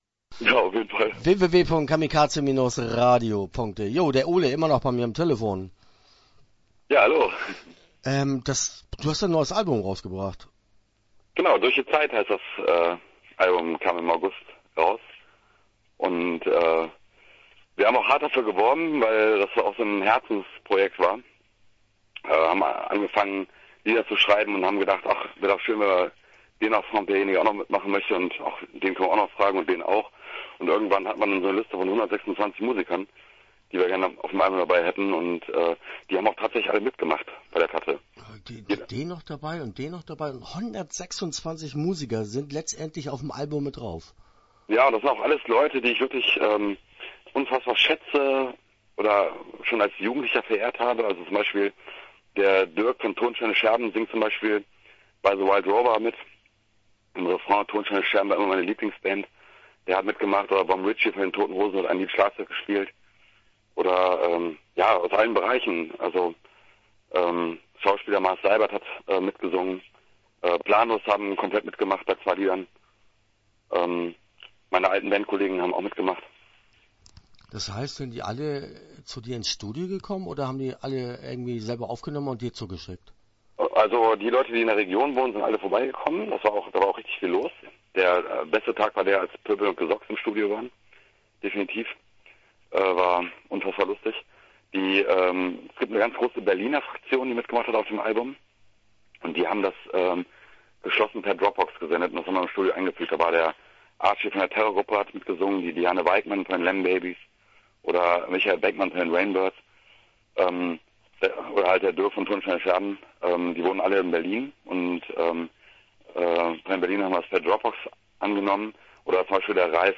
Interview Teil 1